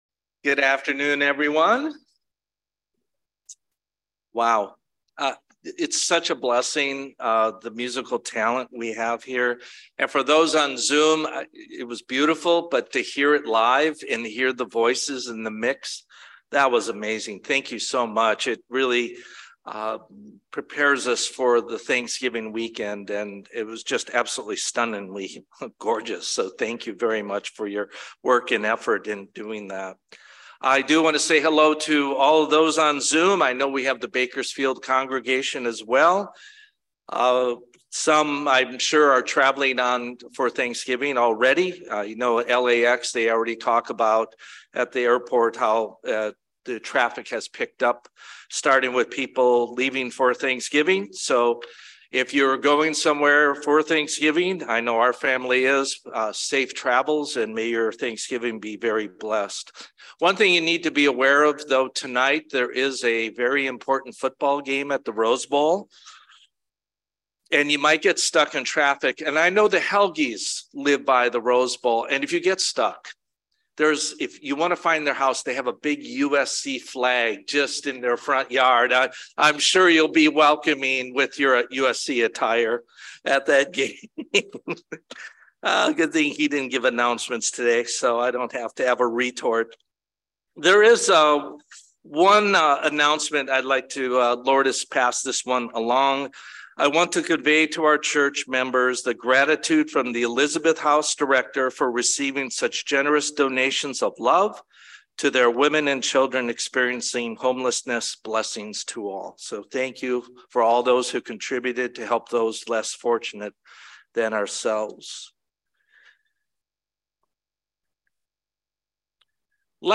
Sermons
Given in Bakersfield, CA Los Angeles, CA